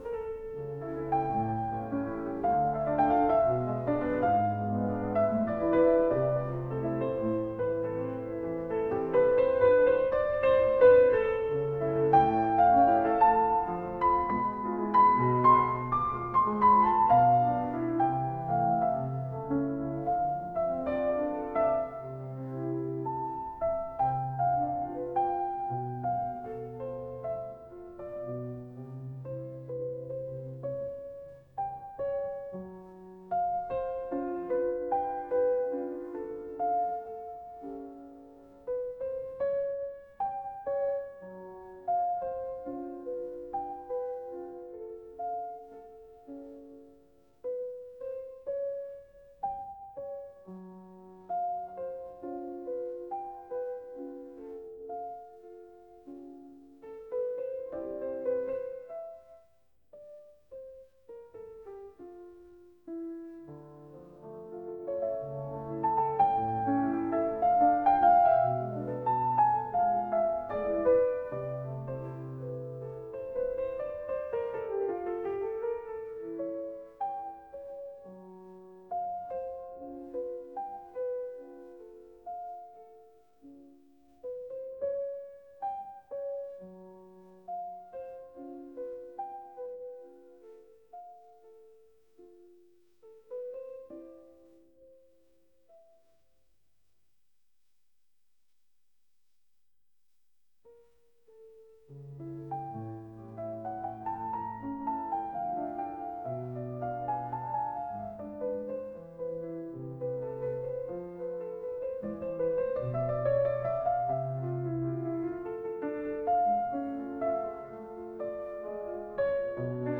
classical | romantic